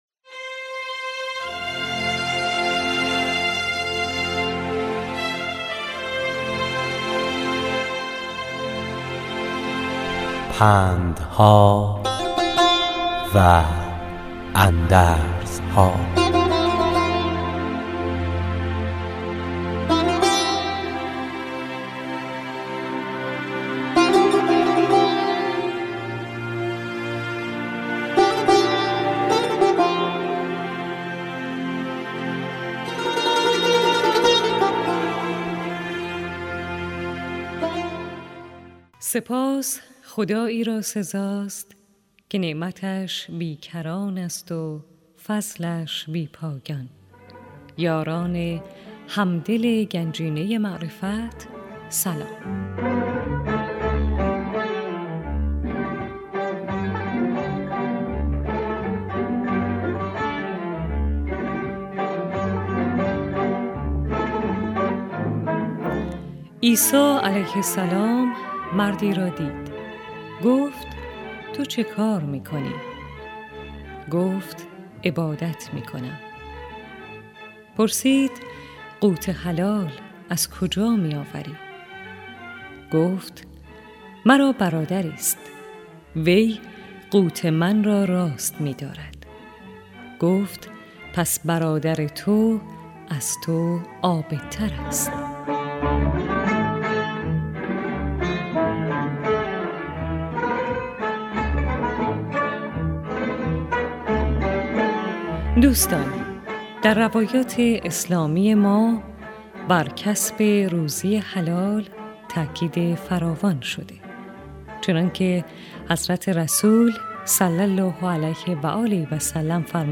راوی برای شنوندگان عزیز صدای خراسان، حکایت های پندآموزی را روایت می کند .